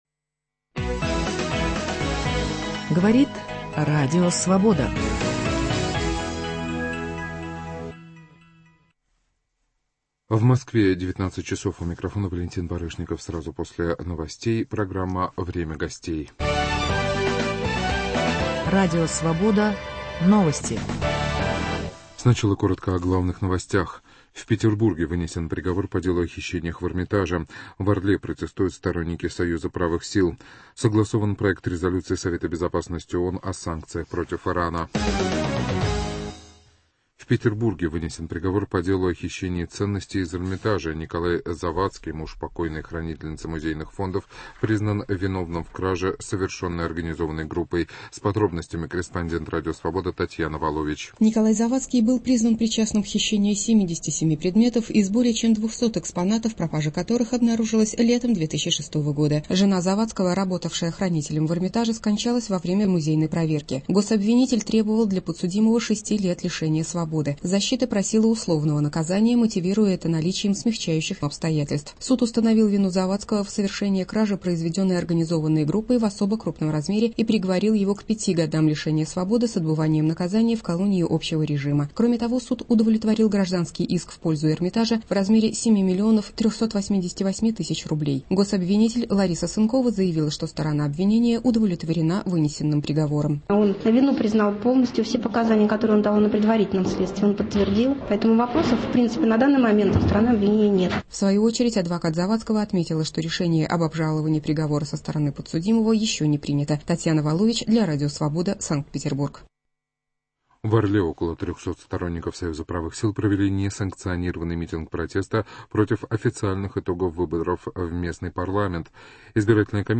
Идет ли Украина в Европу? В киевском бюро Радио Свобода - председатель Народного Руха, бывший министр иностранных дел Украины Борис Тарасюк.